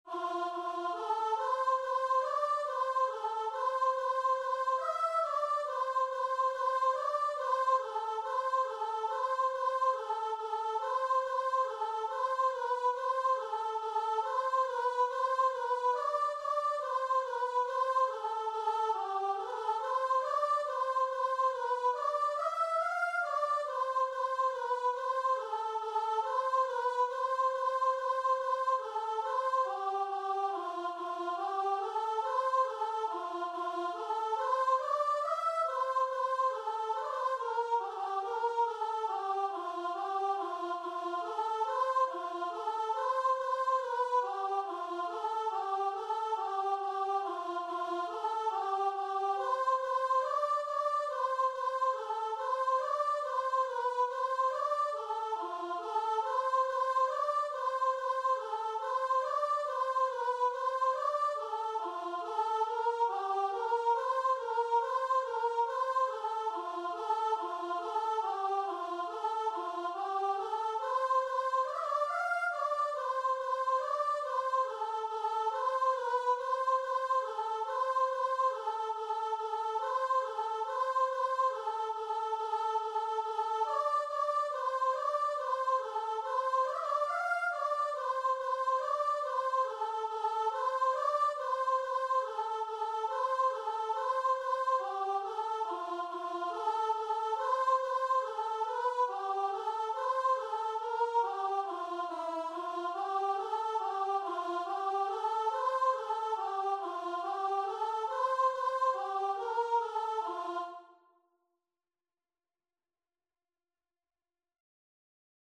Free Sheet music for Voice
Traditional Music of unknown author.
C major (Sounding Pitch) (View more C major Music for Voice )
4/4 (View more 4/4 Music)
Christian (View more Christian Voice Music)